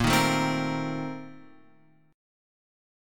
A#M7sus2sus4 chord